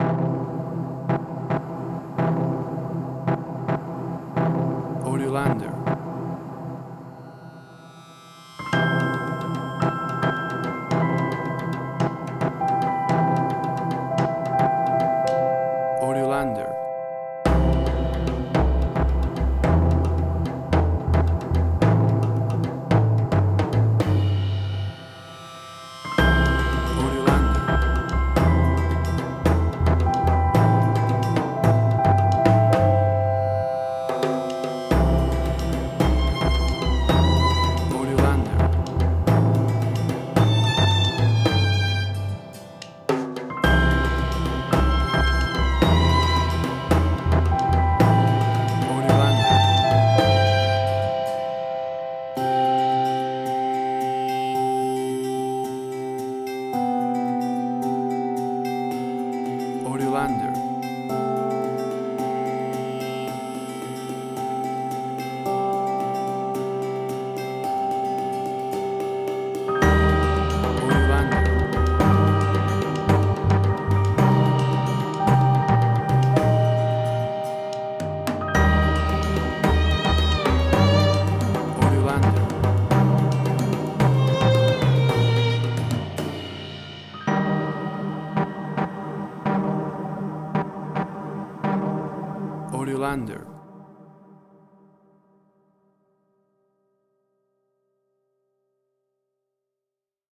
Indie Quirky.
Tempo (BPM): 110